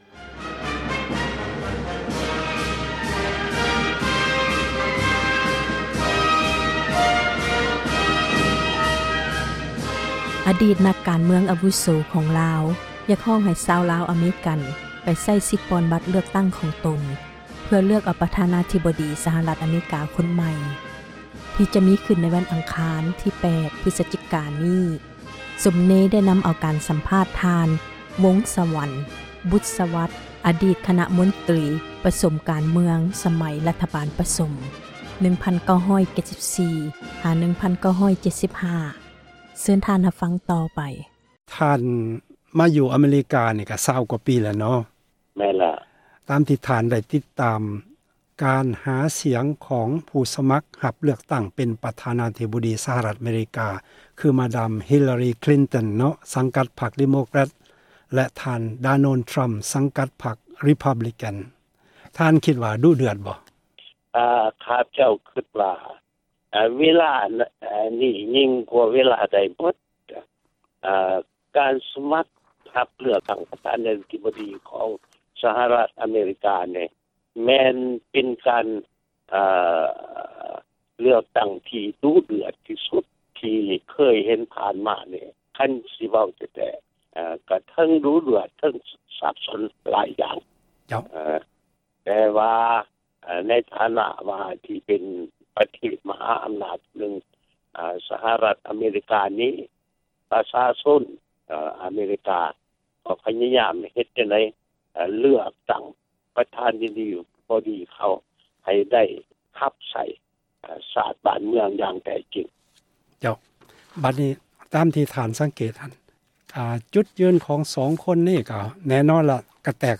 ການສັມພາດ